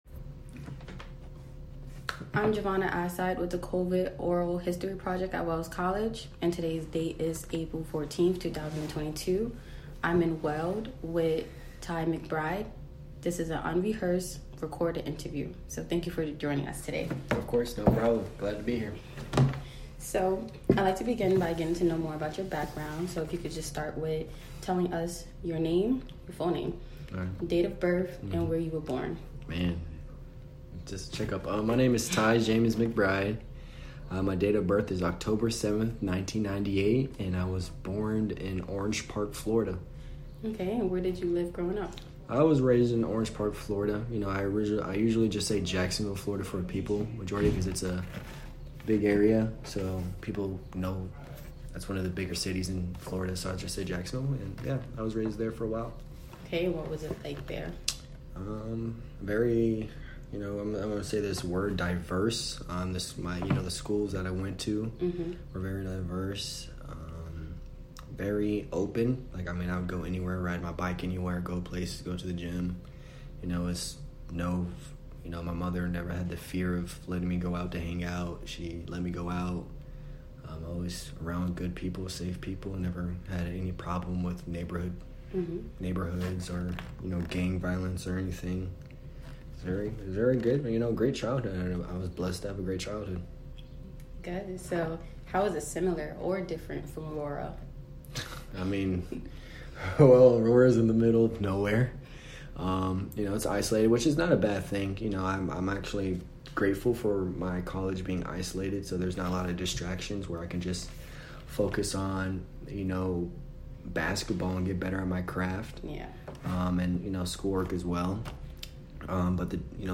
Wells College Center for Oral History